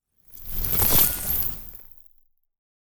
Free Frost Mage - SFX
frozen_armor_03.wav